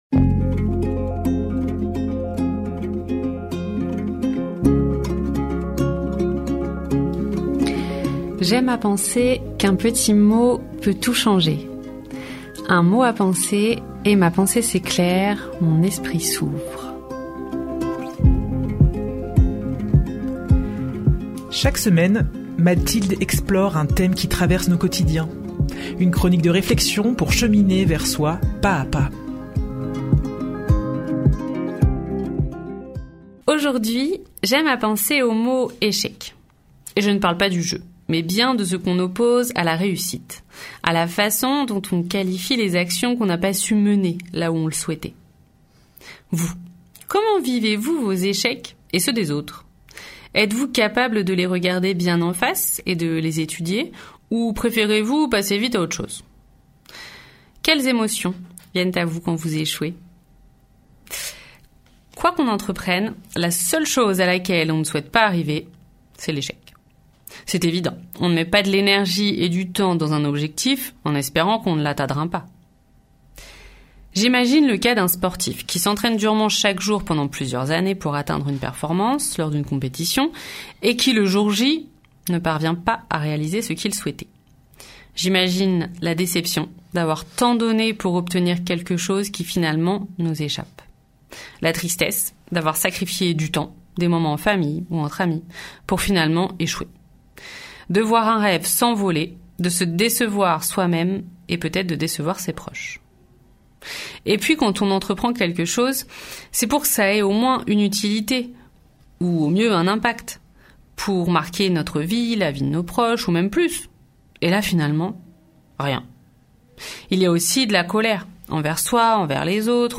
Une nouvelle chronique de réflexion pour cheminer vers soi pas-à-pas.